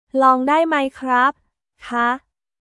ローン ダイ マイ クラップ／カー